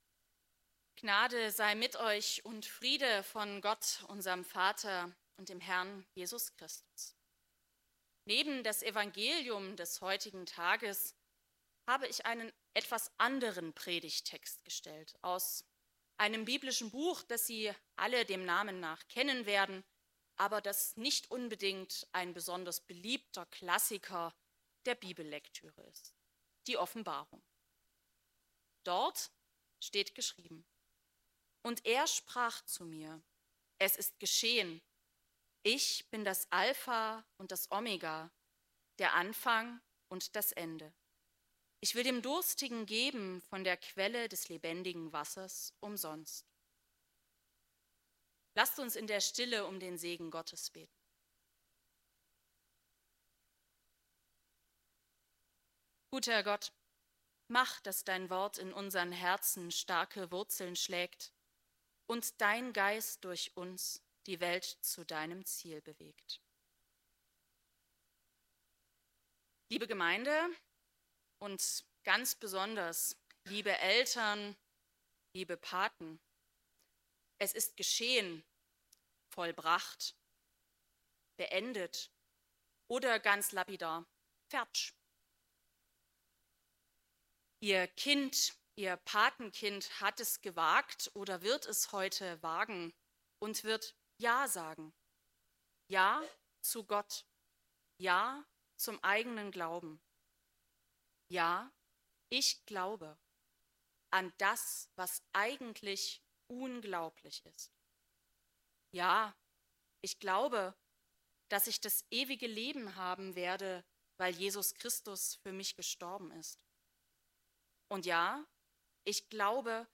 Die Predigt zur Konfirmation in Obercrinitz.